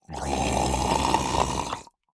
Minecraft Version Minecraft Version snapshot Latest Release | Latest Snapshot snapshot / assets / minecraft / sounds / mob / drowned / idle1.ogg Compare With Compare With Latest Release | Latest Snapshot